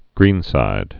(grēnsīd)